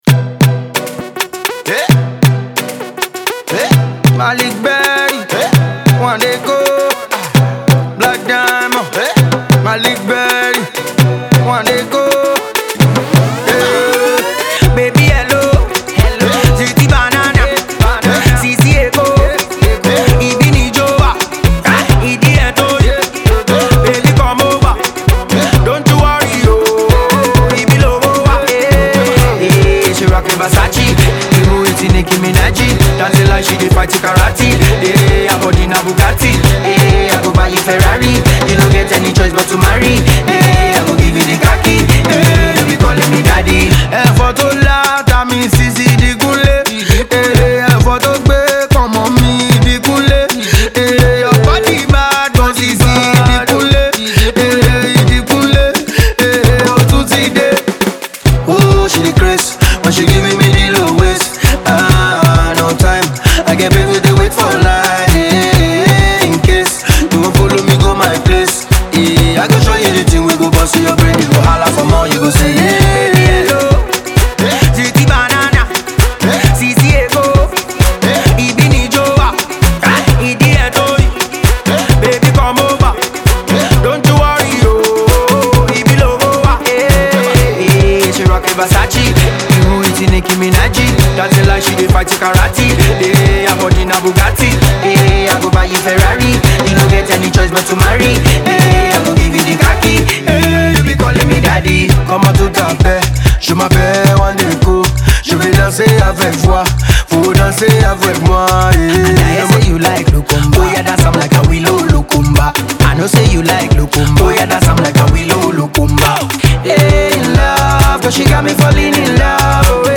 dancey tune
upbeat track